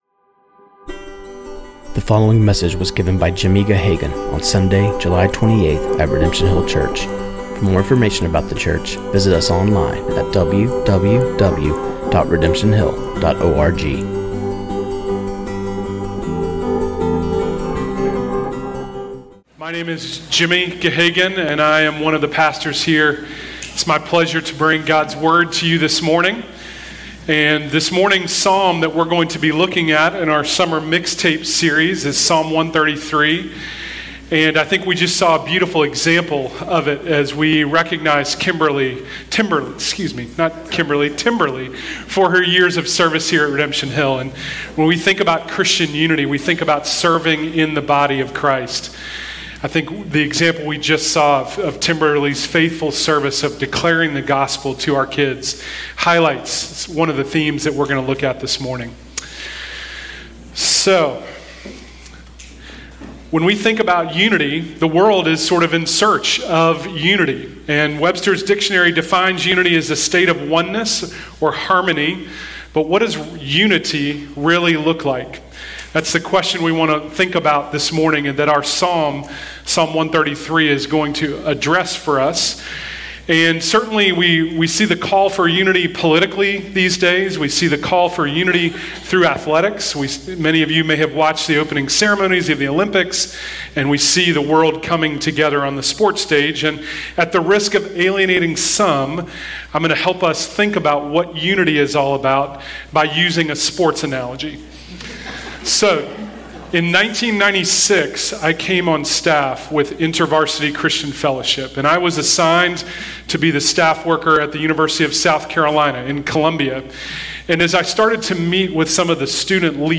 This sermon on Psalm 133:1-3 was preached